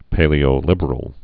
(pālē-ō-lĭbər-əl, -lĭbrəl)